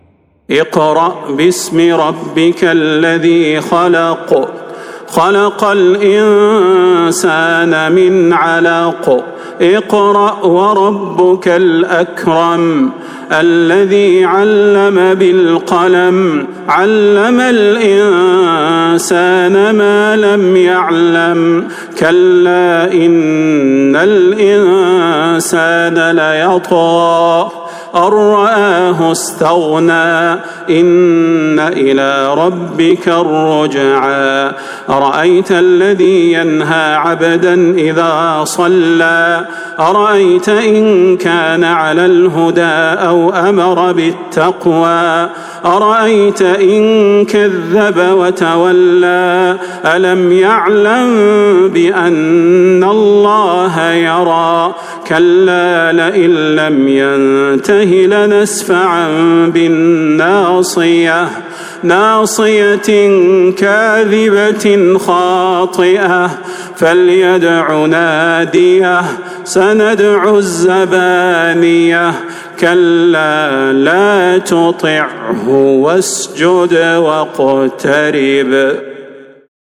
سورة العلق | رمضان 1445هـ > السور المكتملة للشيخ صلاح البدير من الحرم النبوي 🕌 > السور المكتملة 🕌 > المزيد - تلاوات الحرمين